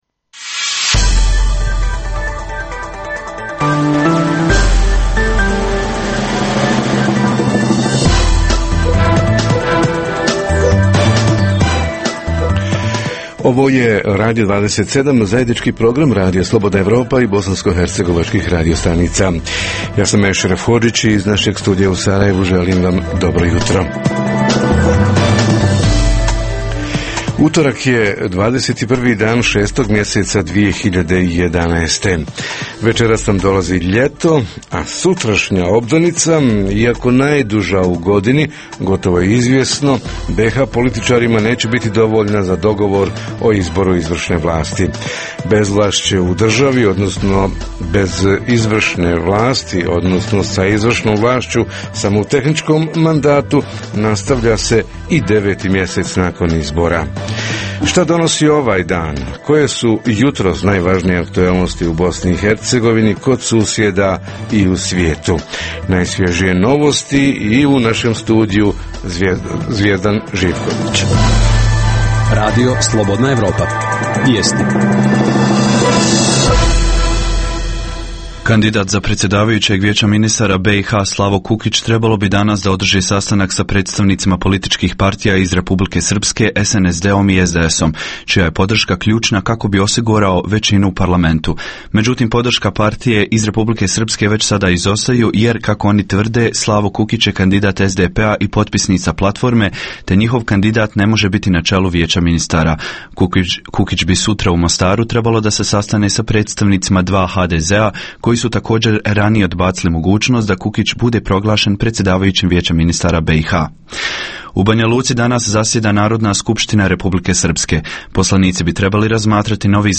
Redovna rubrika Radija 27 utorkom je “Svijet interneta". Redovni sadržaji jutarnjeg programa za BiH su i vijesti i muzika.